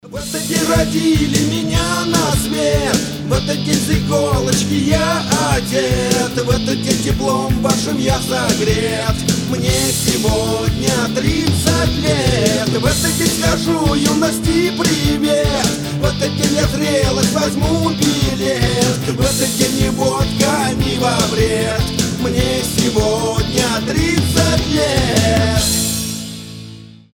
Рингтоны шансон , Рок рингтоны
Панк-рок